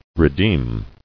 [re·deem]